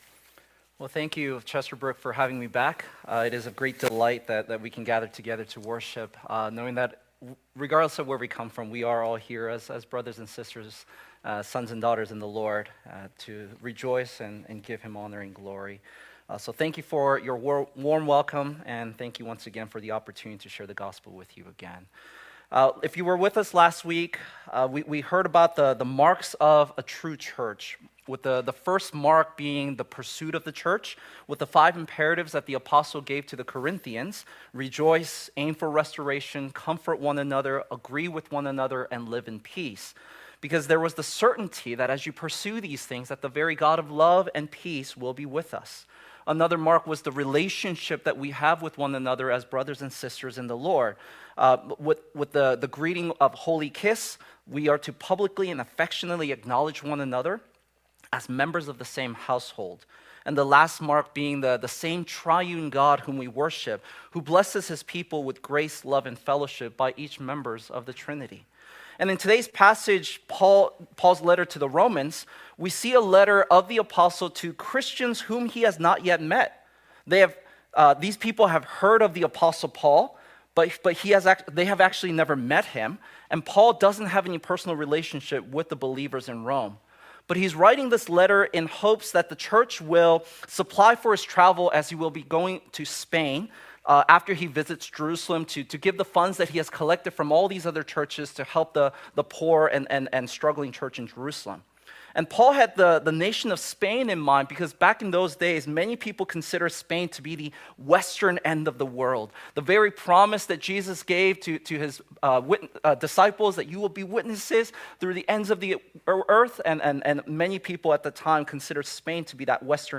Passage: Romans 1:8-15 Service Type: Lord's Day